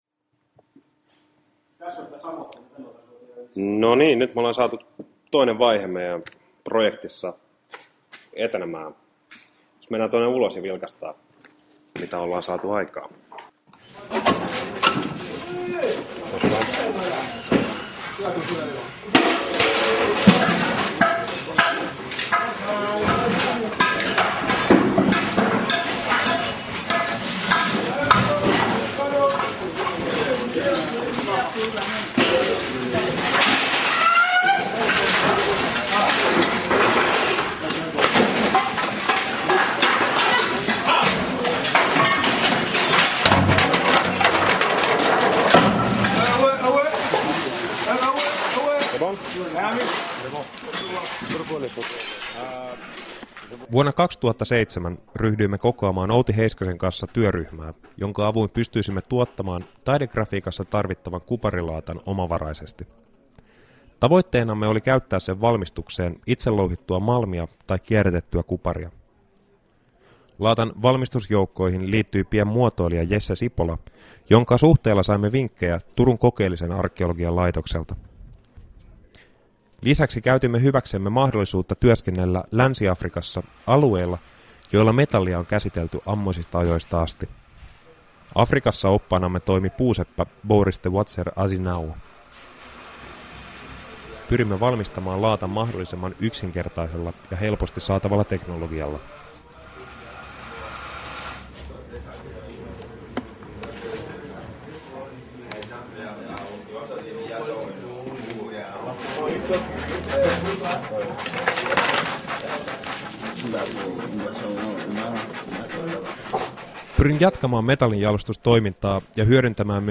01_africa_narrative_documentary.mp3